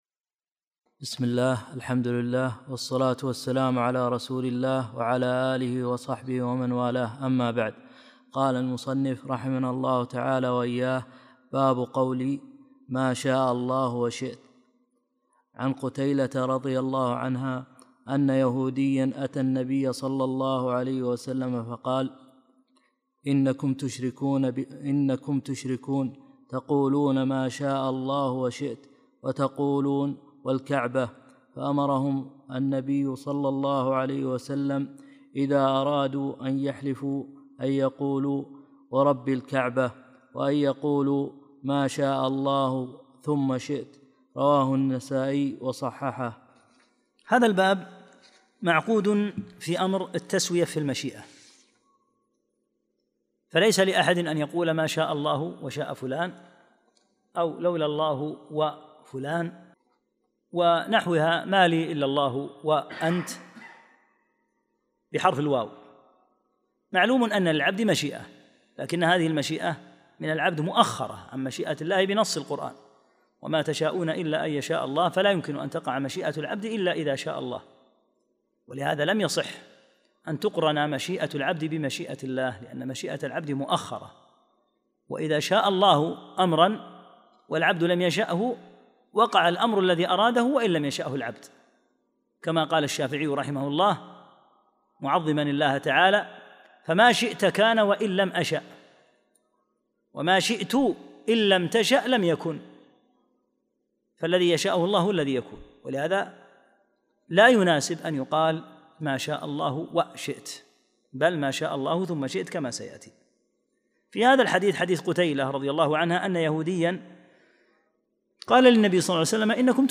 41- الدرس الحادي والأربعون